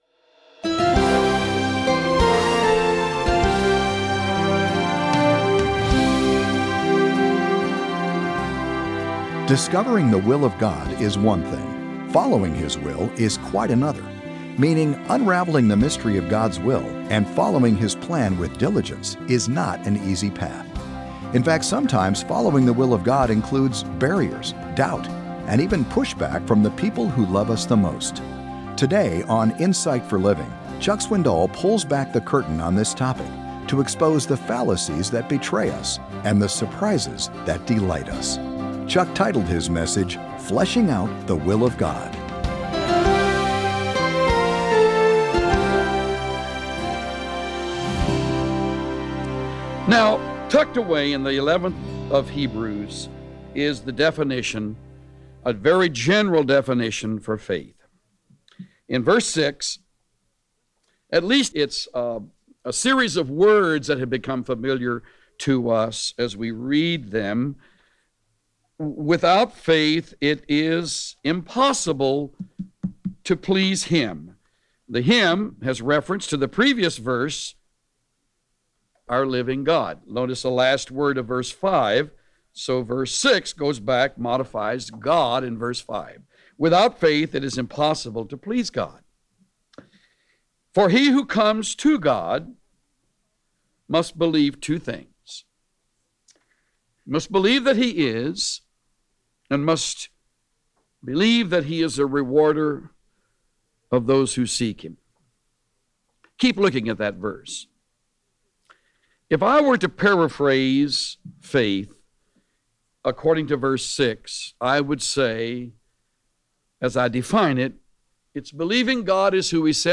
Join the millions who listen to the lively messages of Chuck Swindoll, a down-to-earth pastor who communicates God’s truth in understandable and practical terms—with a good dose of humor thrown in. Chuck’s messages help you apply the Bible to your own life.